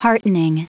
Prononciation du mot heartening en anglais (fichier audio)
Prononciation du mot : heartening